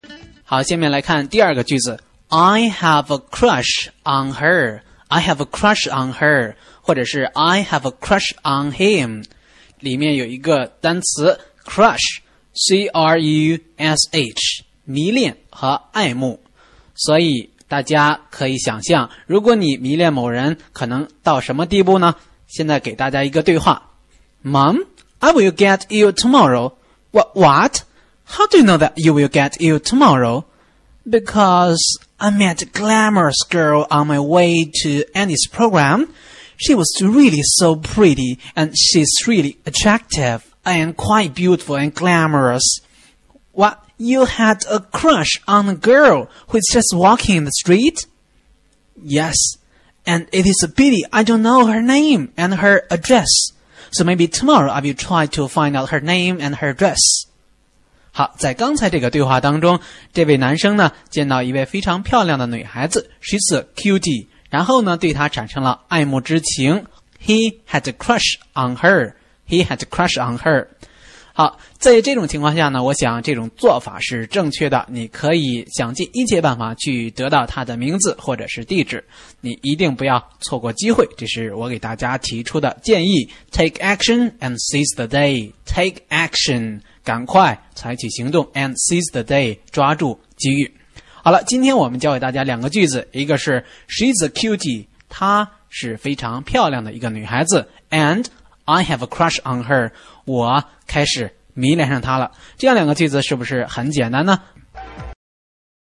对话：